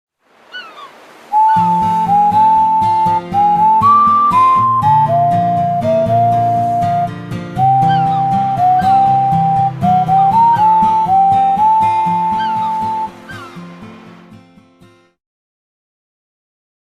волны , гитара , флейта , птицы
спокойные , вода